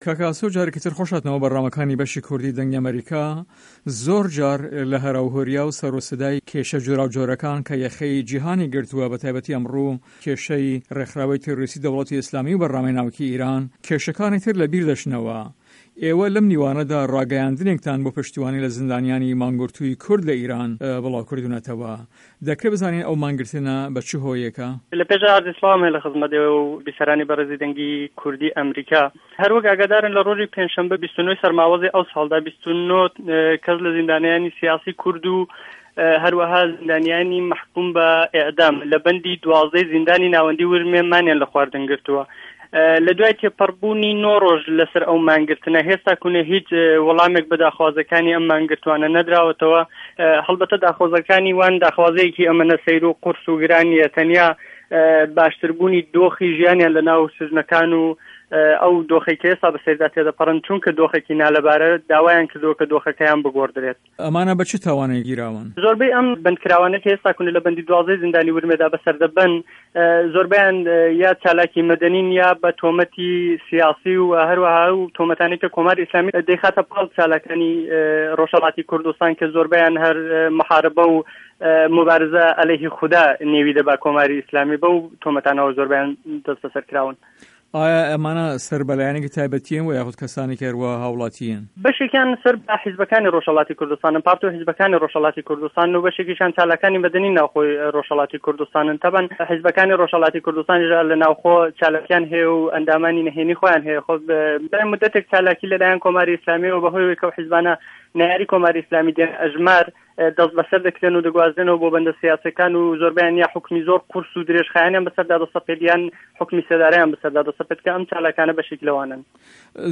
هه‌رێمه‌ کوردیـیه‌کان - گفتوگۆکان